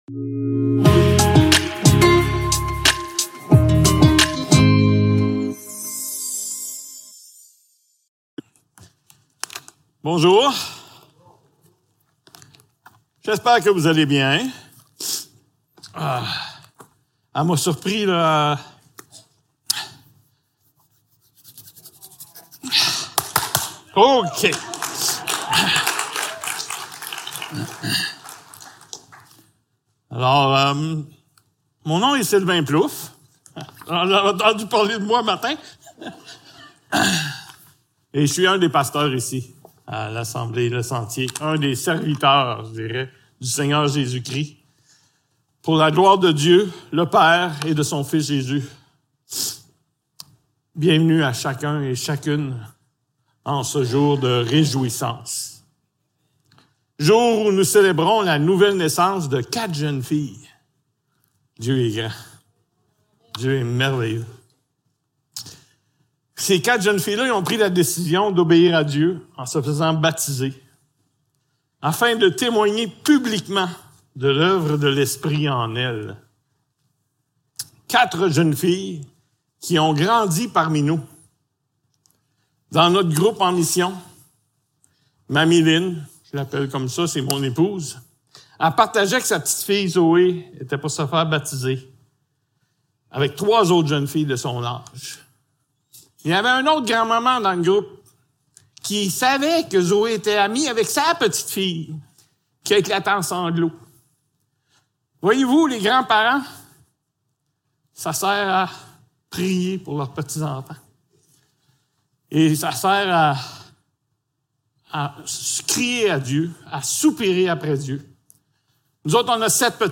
1 Jean 5.1-13 Service Type: Célébration dimanche matin Description